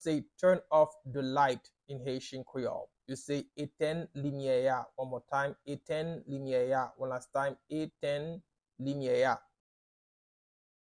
Listen to and watch “Etenn limyè a” audio pronunciation in Haitian Creole by a native Haitian  in the video below:
Turn-off-the-light-in-Haitian-Creole-Etenn-limye-a-pronunciation-by-a-Haitian-Creole-teacher.mp3